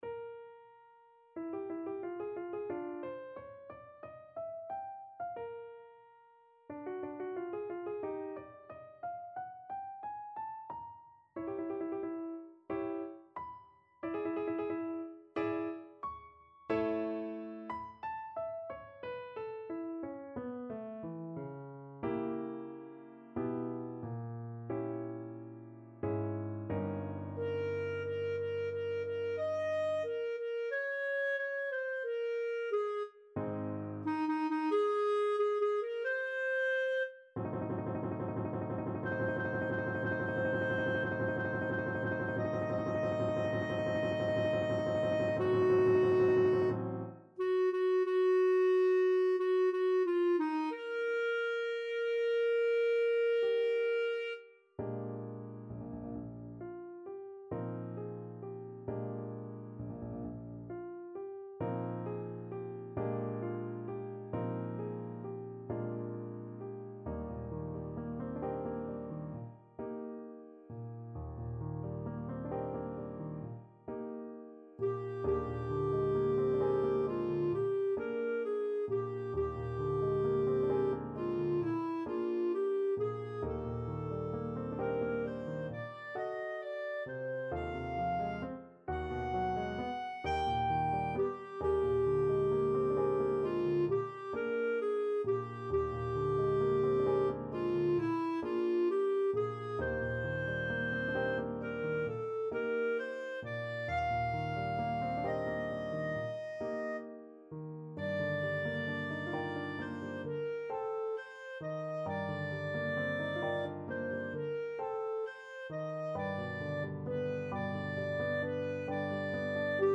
Clarinet
Eb major (Sounding Pitch) F major (Clarinet in Bb) (View more Eb major Music for Clarinet )
Moderato =90
4/4 (View more 4/4 Music)
D5-B6
Classical (View more Classical Clarinet Music)